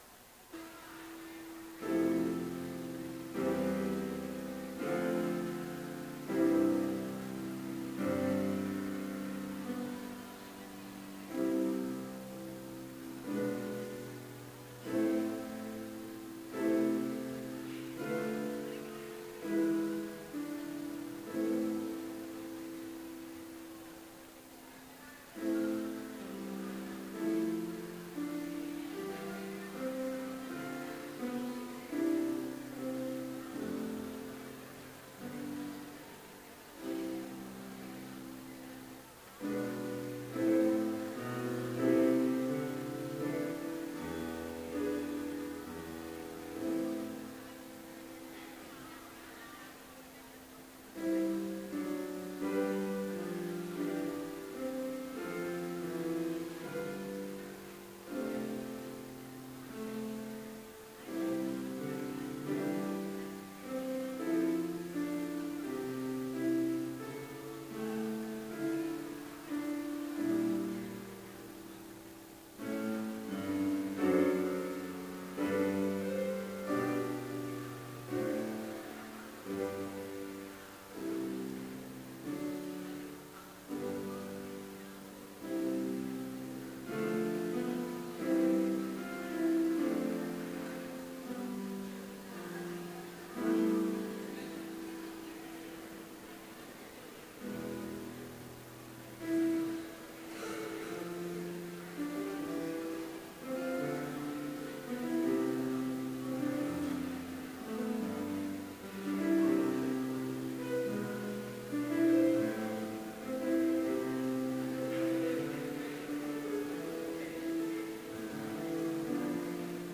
Complete service audio for Chapel - February 26, 2016